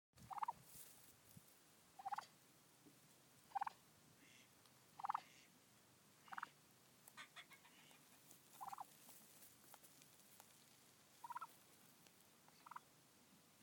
Call of the turkey